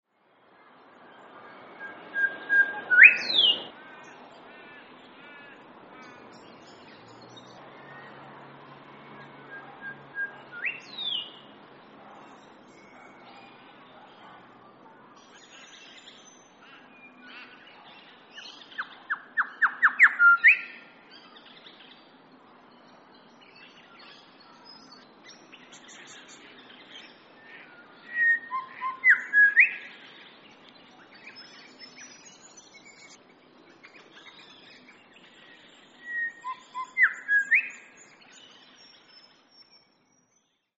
Grey Shrikethrush - Colluricincla harmonica
Voice: varied melodious trills and chortles, clear ringing call.
Call 2: shorter calls
Grey_Shrikethr_short_call.mp3